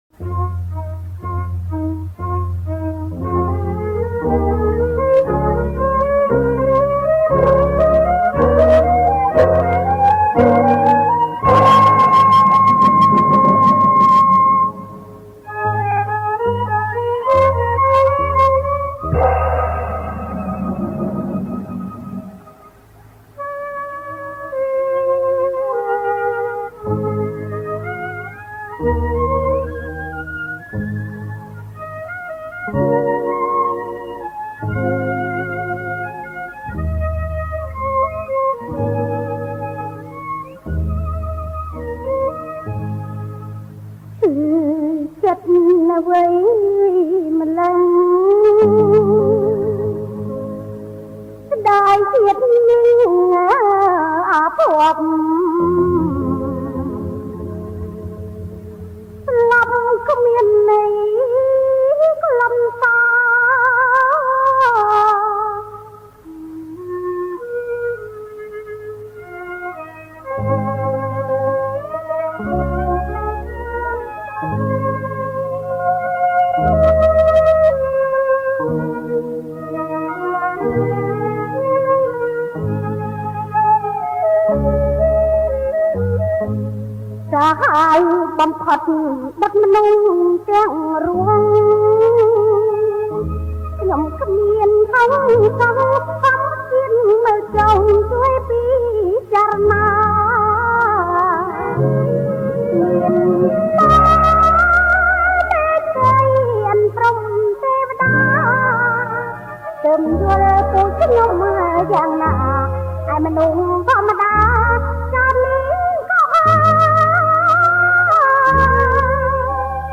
• ប្រគំជាចង្វាក់ Slow